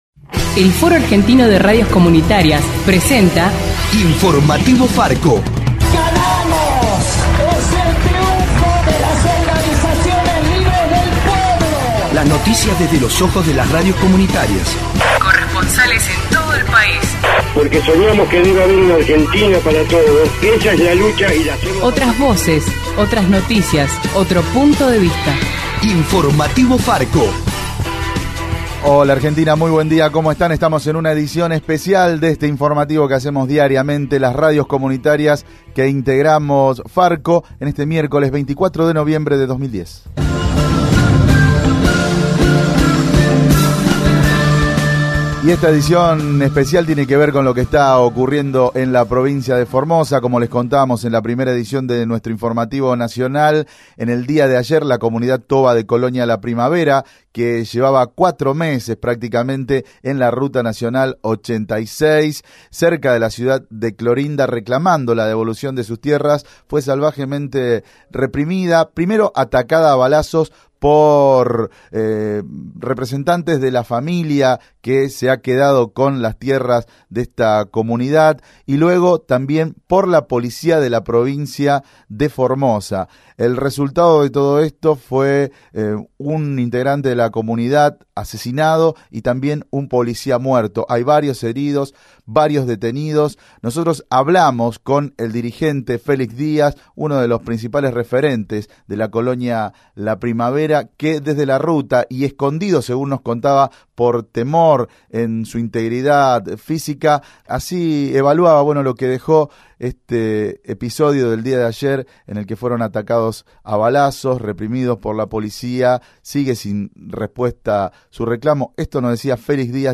• Especial de Farco (Foro Argentino de Radios Comunitarias) sobre la represión y muerte en Formosa.